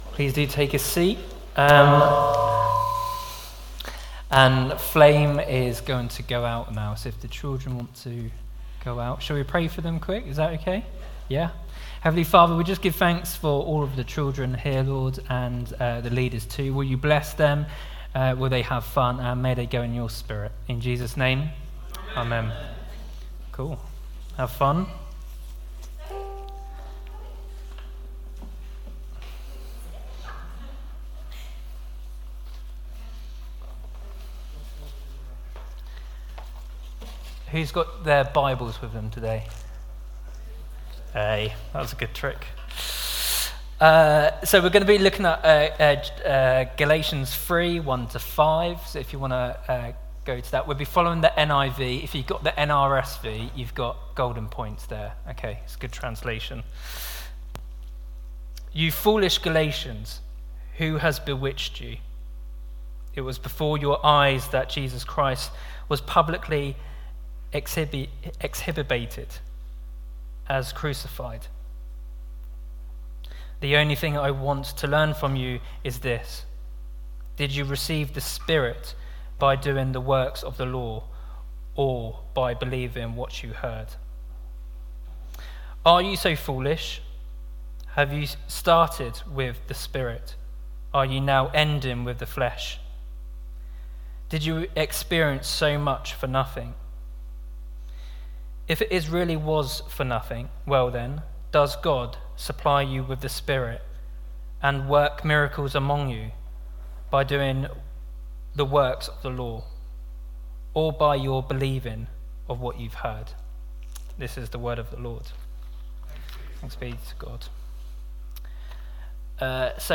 Sermon 16th July 2023 11am gathering
We have recorded our talk in case you missed it or want to listen again.